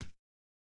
Bare Step Stone Hard D.wav